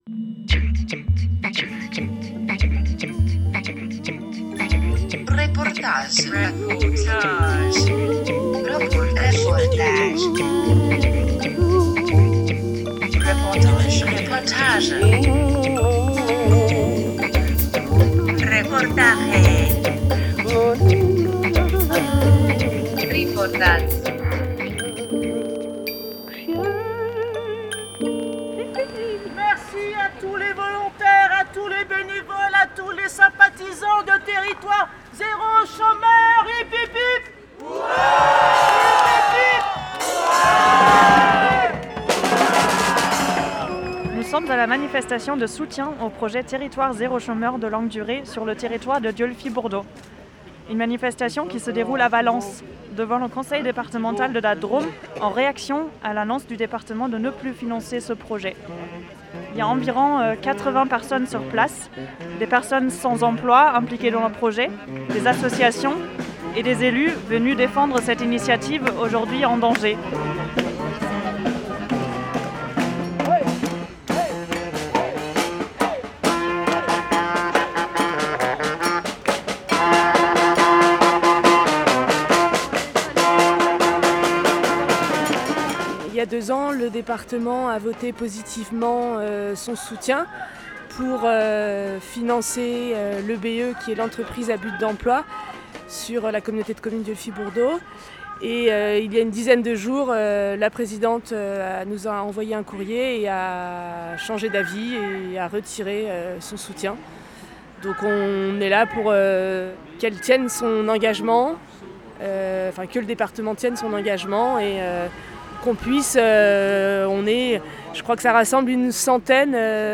5 décembre 2024 14:36 | Actualités, reportage
RadioLà était à la manifestation qui s’est tenue lundi 2 décembre à Valence, devant le Conseil départemental.
Manif-de-soutien-au-projet-Territoire-Zero-Chomeur-a-Valence_decembre-2024_PAD.mp3